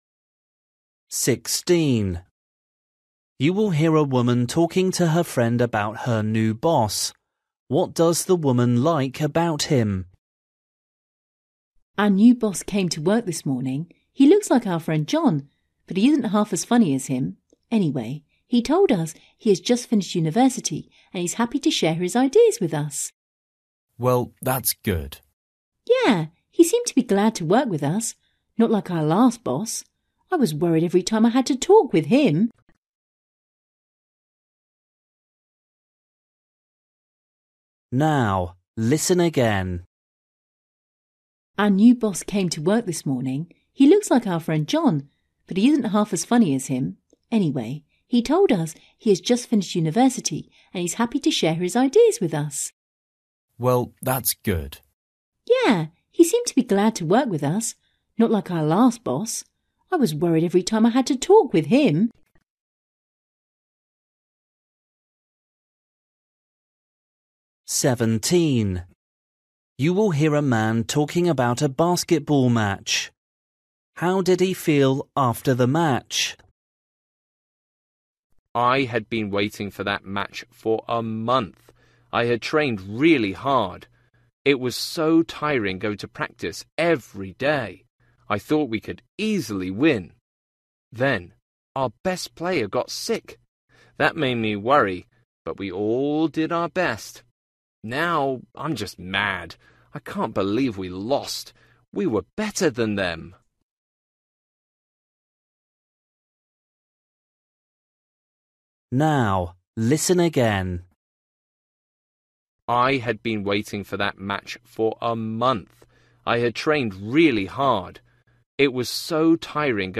Listening: everyday short conversations
16   You will hear a woman talking to her friend about her new boss.
17   You will hear a man talking about a basketball match.
18   You will hear a teacher talking about a lesson.
19   You will hear a woman talking to her friend about her house.
20   You will hear a man talking about a job.